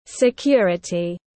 Security /sɪˈkjʊə.rə.ti/